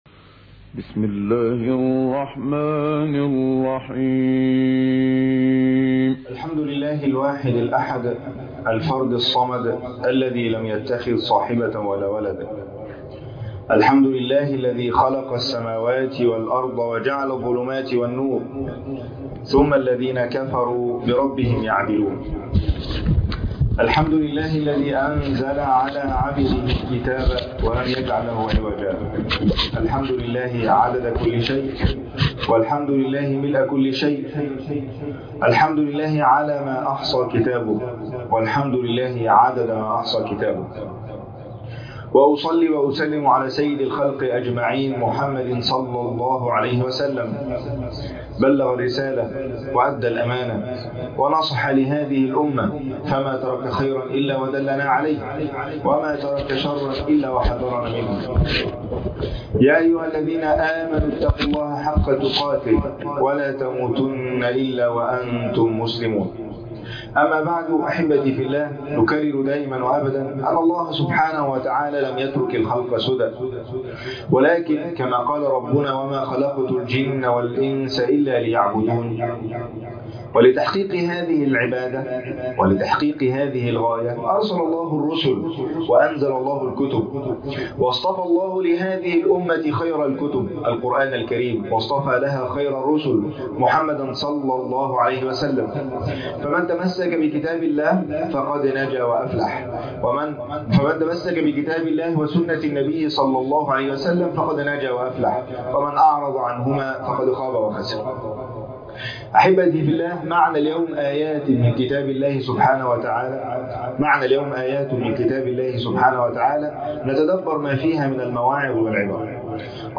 لا تبطلوا صدقاتكم بالمن والأذى | شرح أمثال الإنفاق في سورة البقرة | خطبة جمعة